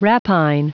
Prononciation du mot rapine en anglais (fichier audio)
Prononciation du mot : rapine